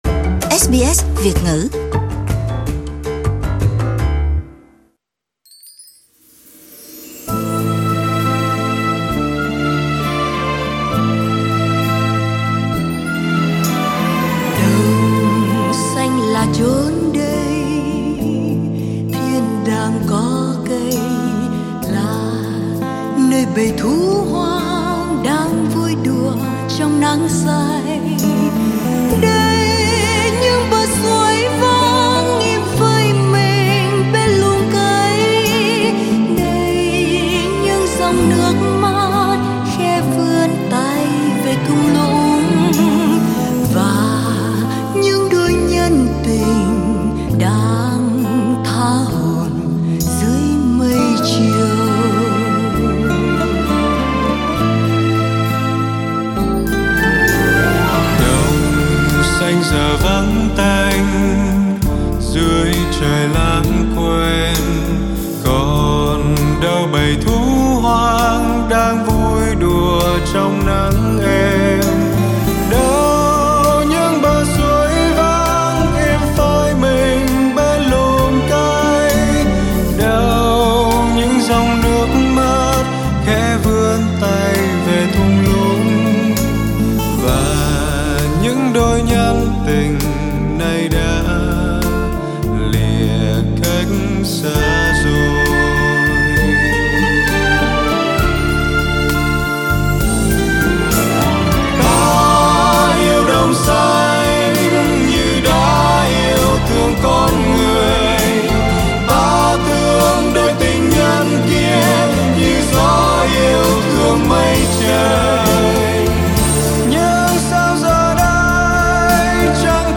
Cùng Văn Nghệ Cuối Tuần thưởng thức những giọng ca và những tác phẩm sẽ xuất hiện trong chương trình.